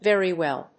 アクセントVèry wéll.